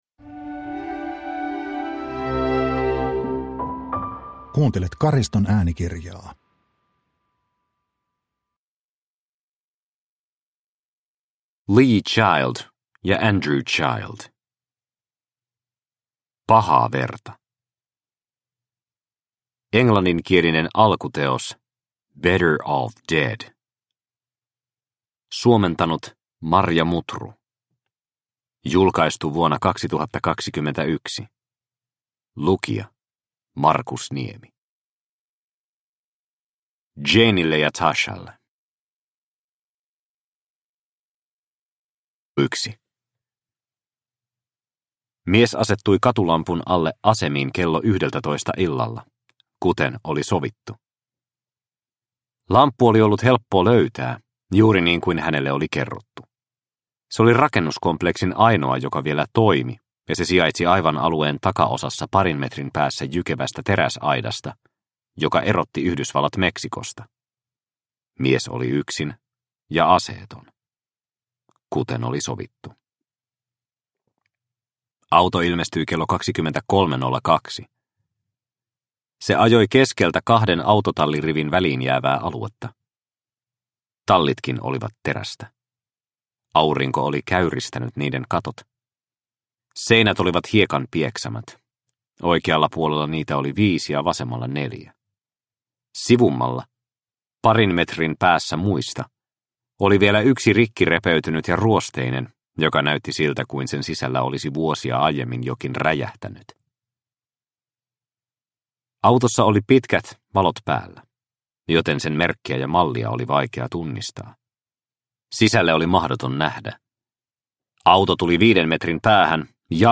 Pahaa verta – Ljudbok – Laddas ner